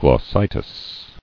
[glos·si·tis]